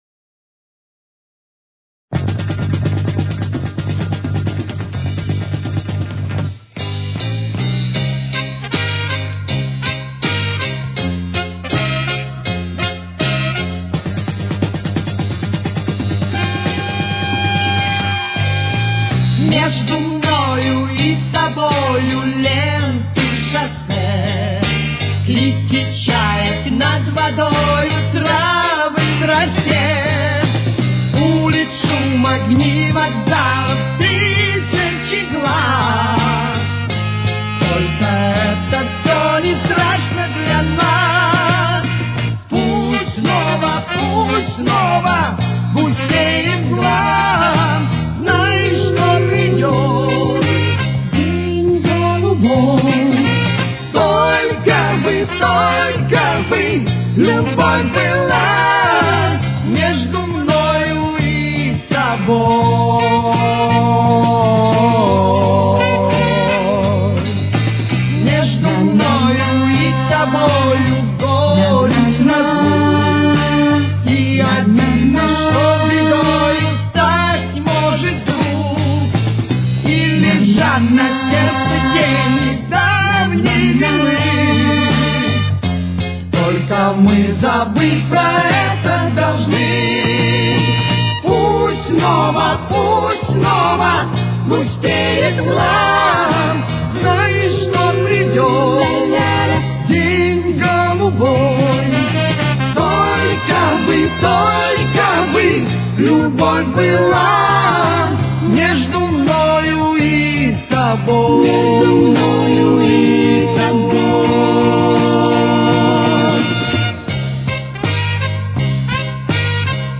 Тональность: До минор. Темп: 85.